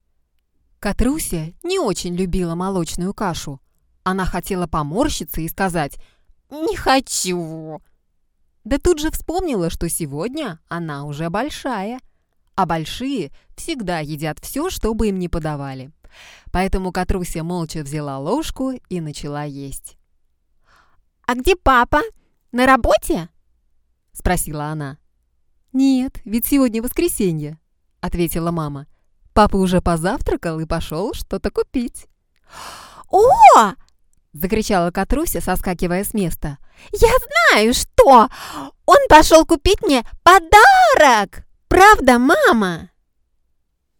Kein Dialekt
Sprechprobe: Sonstiges (Muttersprache):
Can do Child, Young Female, Middle Age Female, Senior Female timbre of voice.